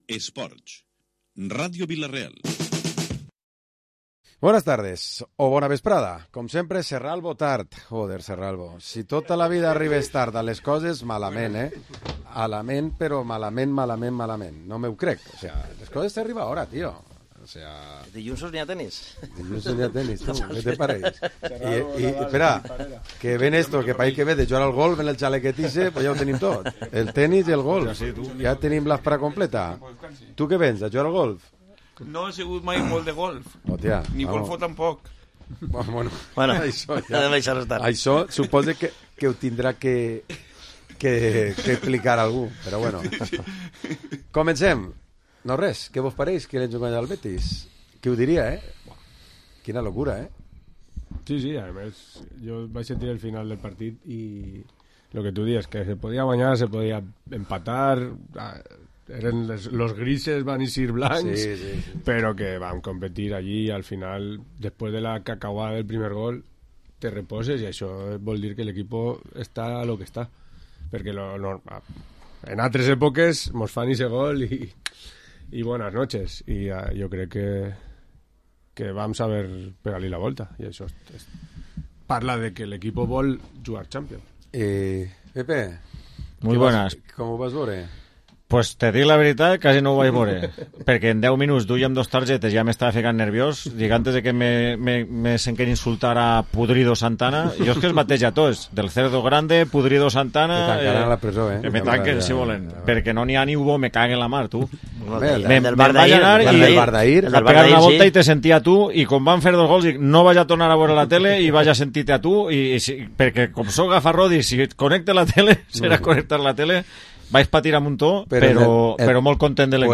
Programa Esports dilluns tertúlia 14 d’abril